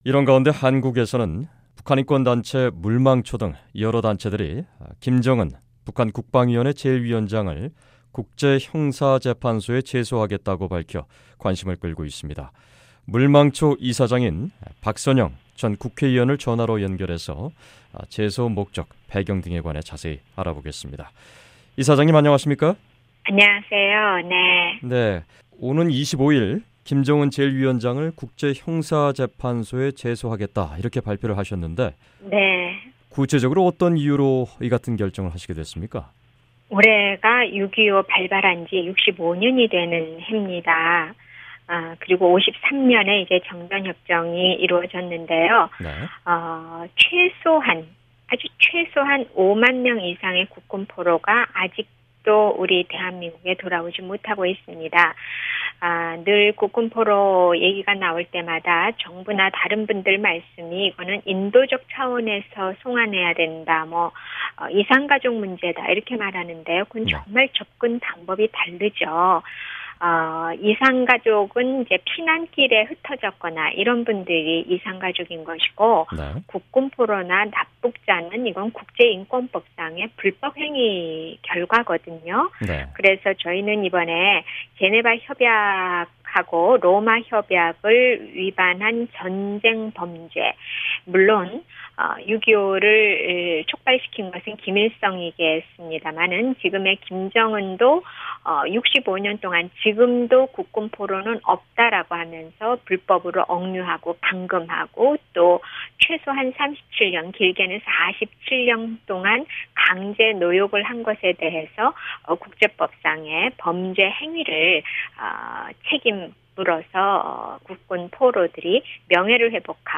[인터뷰] 북한인권단체 ‘물망초’ 박선영 이사장